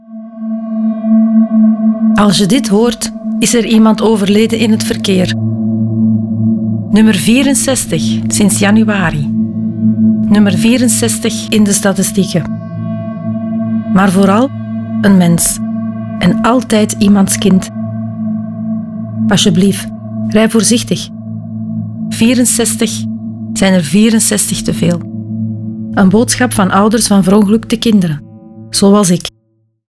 Ouders van Verongelukte Kinderen maken een radiospot voor elk dodelijk verkeersslachtoffer.
De spots werden geproduced door Raygun met de medewerking van échte ouders van verongelukte kinderen, geen acteurs.